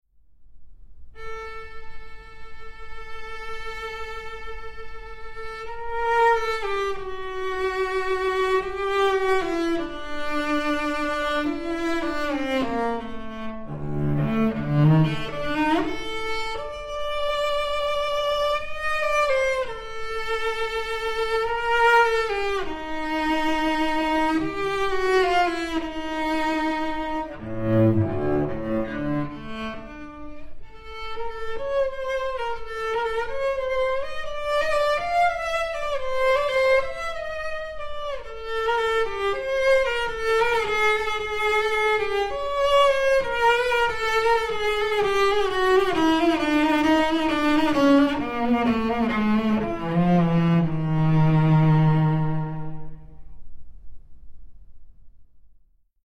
大提琴
這把大提琴的琴音非常厚實宏亮，可以在容納上千人的演出場地中非常清楚地被聽見每一個音，就像一位統治天下的君王，能夠居高臨下的跟他的子民們演說一般的有氣勢。
musicCelloAmati1566.mp3